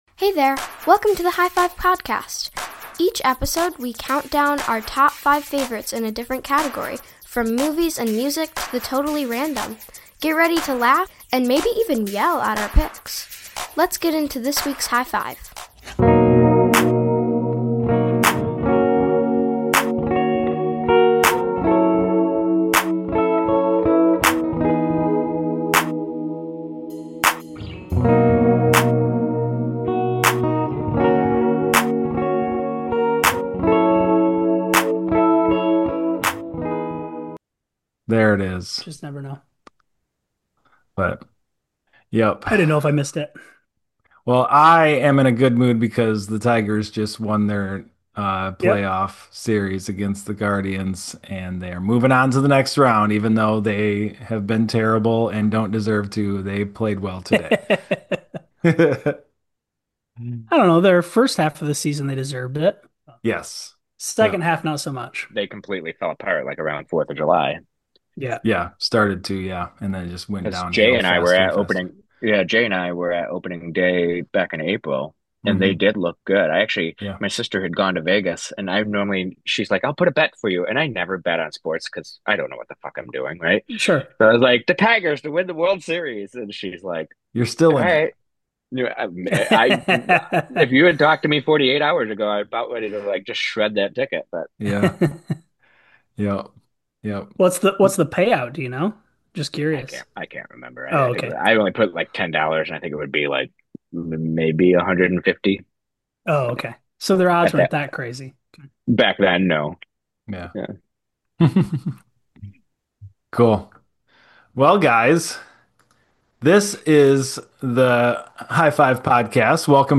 From old favorites to new obsessions, they reflect on how their own listening (and hosting) habits have changed over time. Join The Hi-Five crew for a milestone conversation full of laughs, insight, and a little nostalgia.